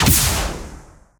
GUNArtl_Rocket Launcher Fire_04_SFRMS_SCIWPNS.wav